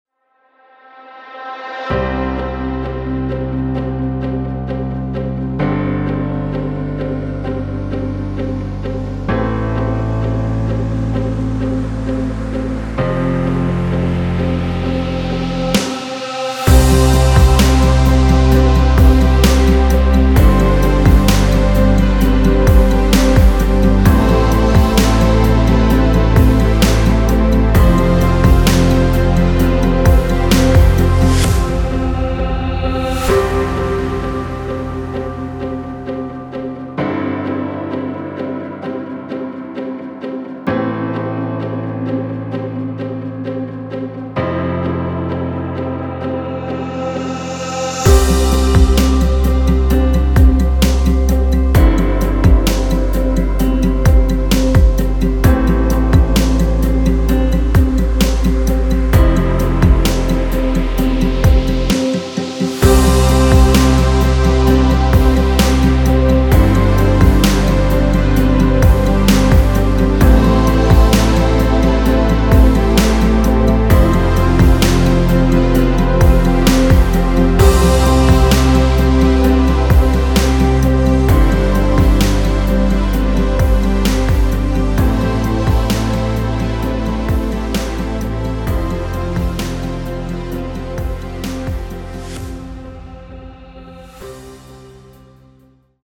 Popschlagersong im aktuellen Style.
Hier kannst du kurz ins Playback reinhören.
BPM – 130
Tonart – C-major